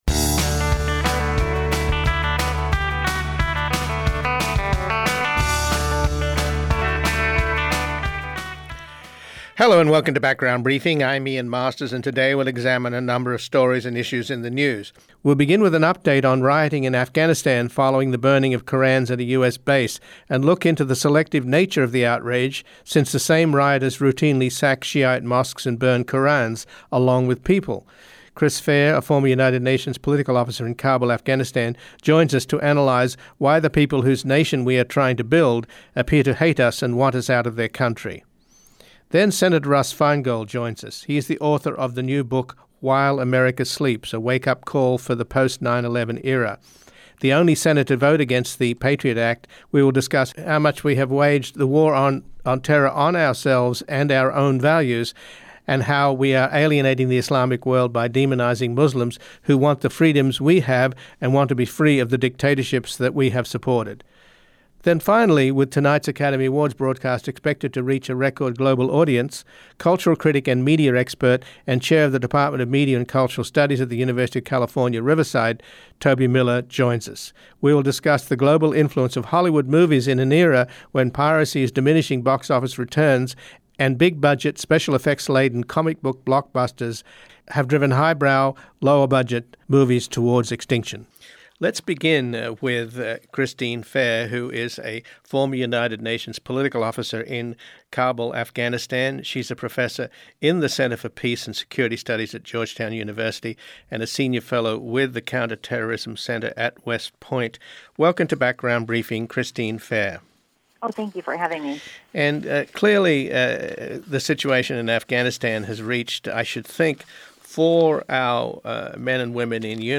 an intelligence and foreign affairs analyst joins us to discuss the quality of this data dump. Part 2 Then we look into the Obama Administration’s proposed overhaul of corporate taxation and how much of corporate America’s record profits are being hidden abroad in tax shelters.